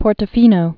(pôrtə-fēnō, -tō-)